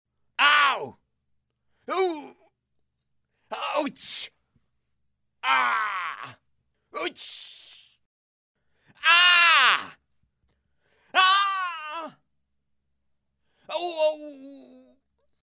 Крик от боли при лечении зубов